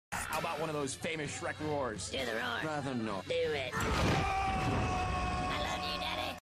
Play, download and share SHRAK original sound button!!!!
do-the-roar.mp3